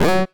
pgs/Assets/Audio/Comedy_Cartoon/cartoon_funny_wrong_answer_01.wav
cartoon_funny_wrong_answer_01.wav